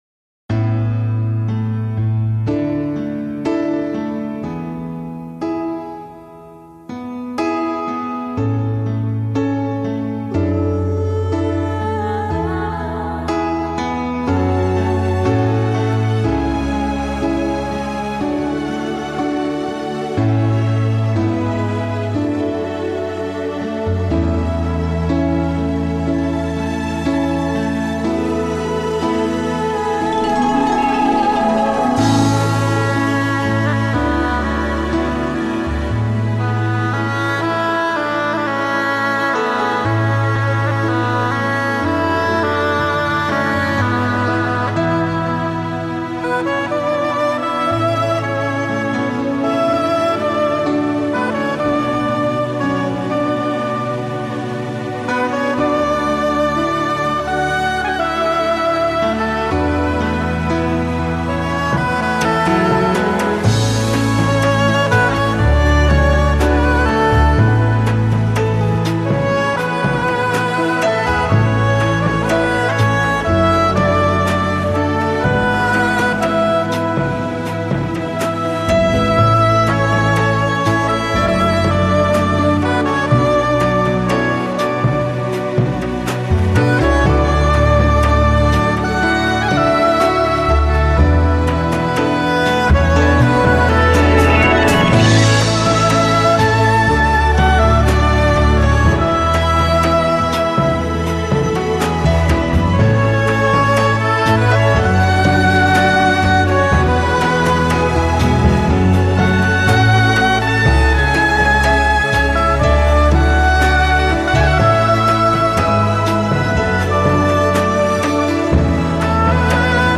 很喜欢这张精选，喜欢编者挑选曲目的精心安排，全然的舒服和放松，